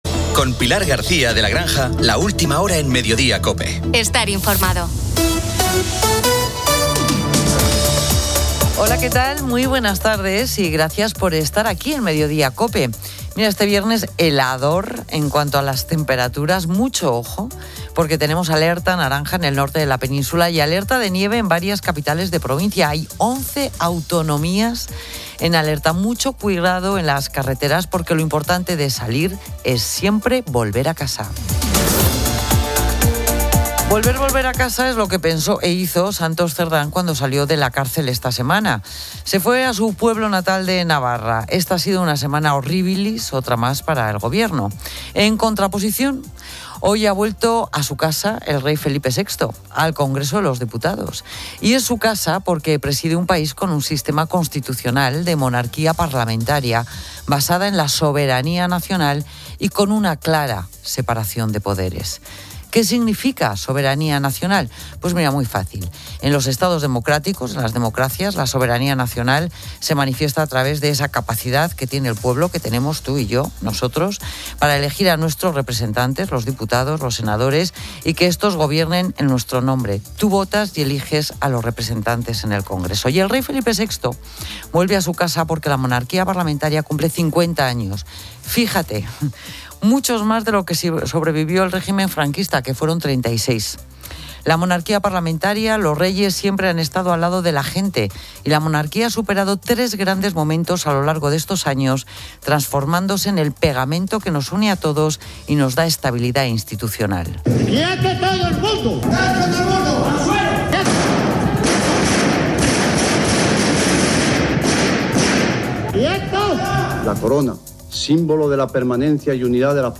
Pilar García de la Granja presenta COPE.